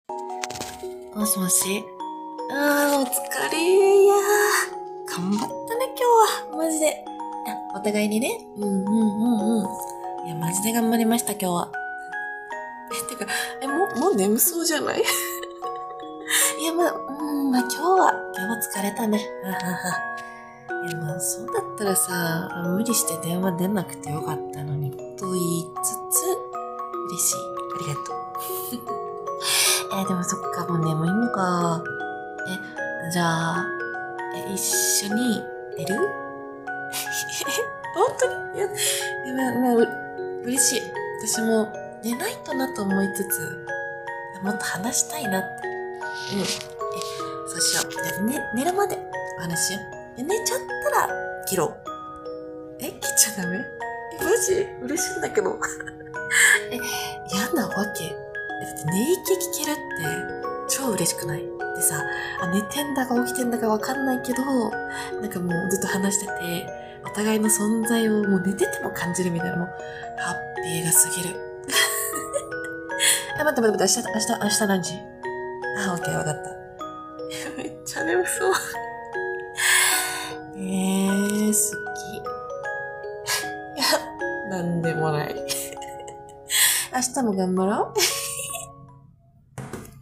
【1人用声劇台本】